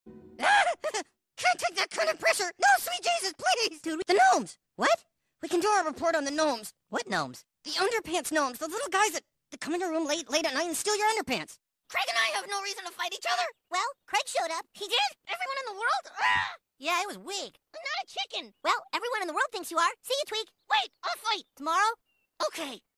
i love his deeper voice sound effects free download